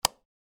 UI_Close.mp3